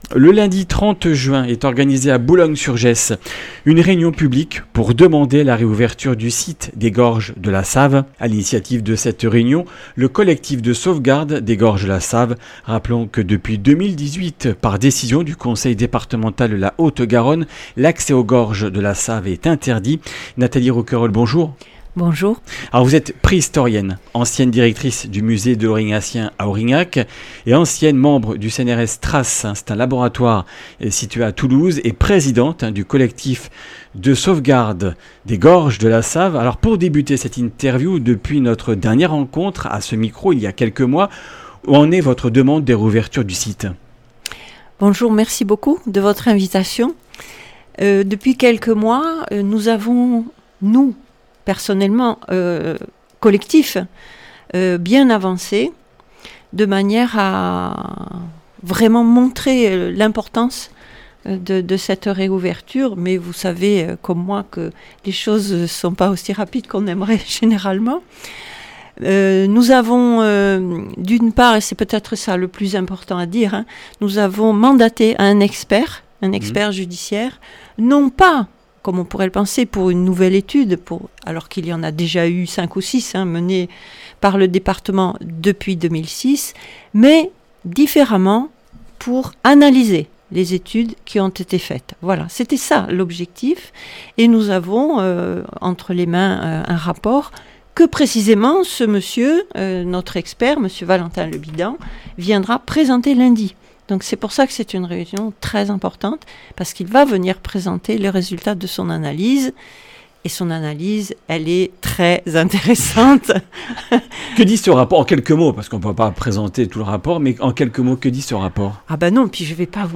Comminges Interviews du 25 juin